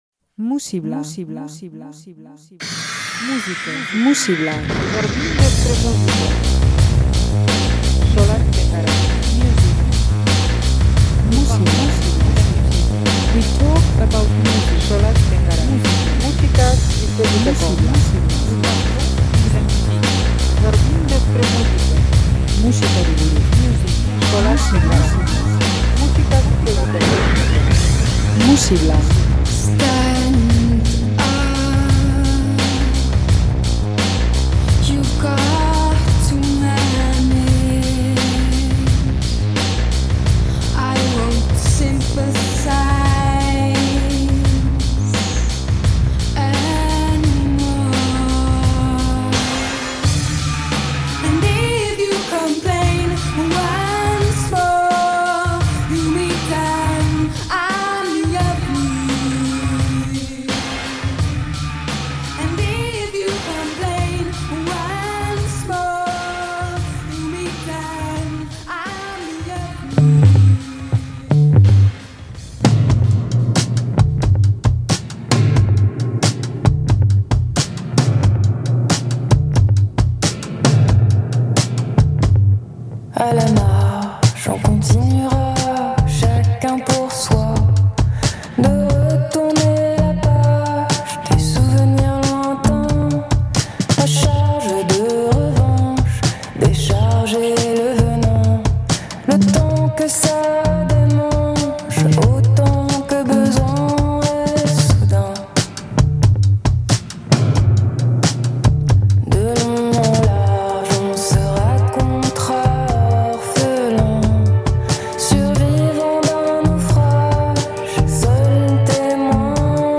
Frantziako ye-ye berria.